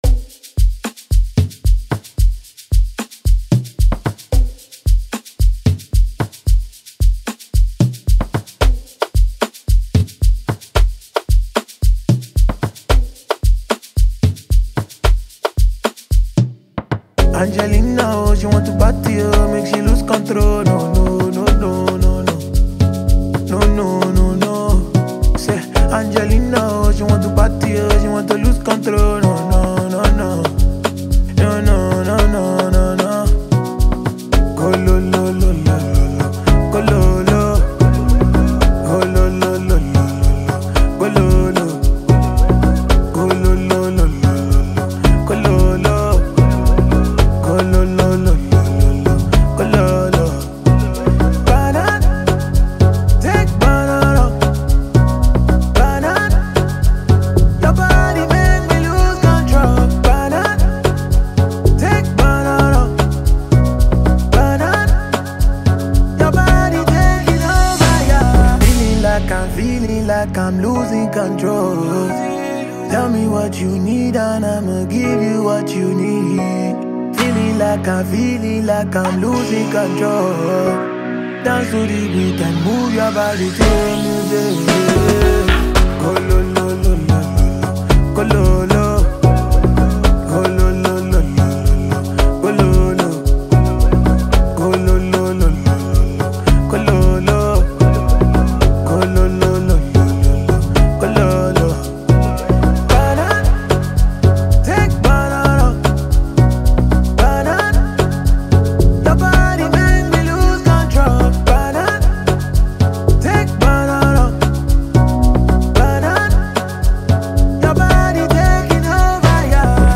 Amapiano influenced record